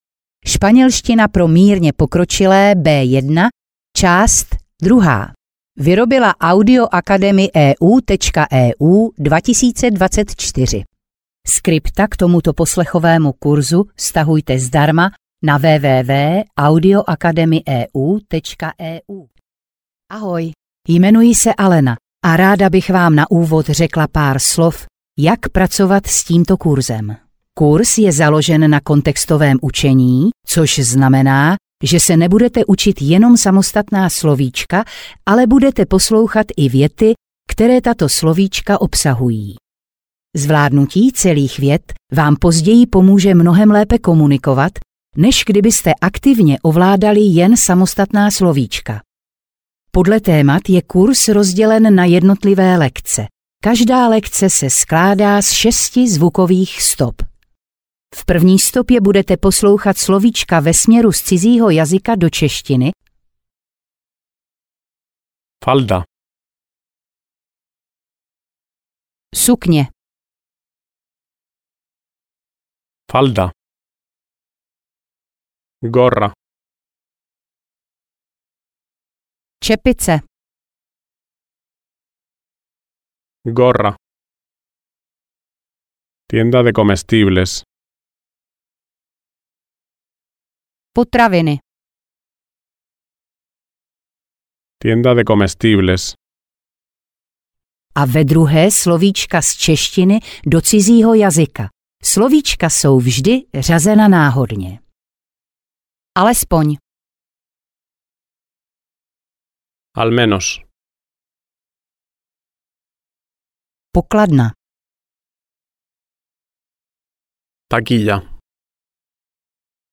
Španělština pro mírně pokročilé B1 – část 2 audiokniha
Ukázka z knihy
Jakmile budete zvládat překládat věty ze španělštiny do češtiny (lekce 6) v časové pauze před českým překladem, tak jste vyhráli.